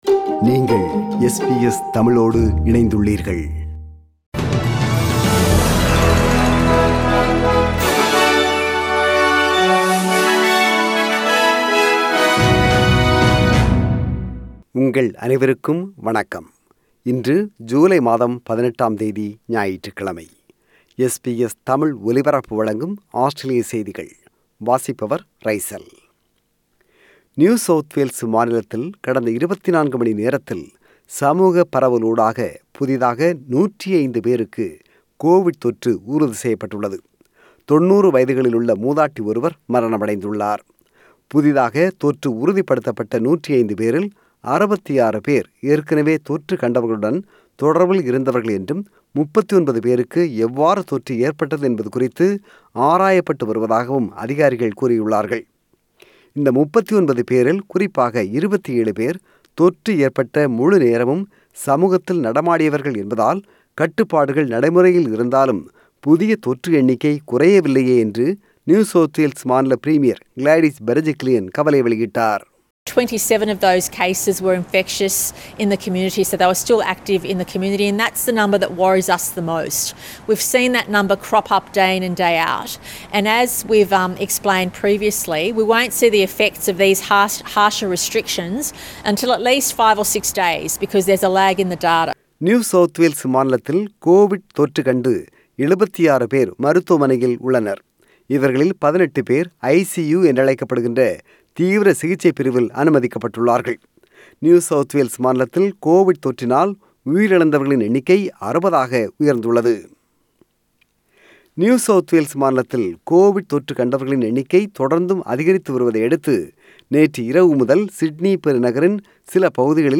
ஆஸ்திரேலிய செய்திகள்: 18 ஜூலை 2021 ஞாயிற்றுக்கிழமை வாசித்தவர்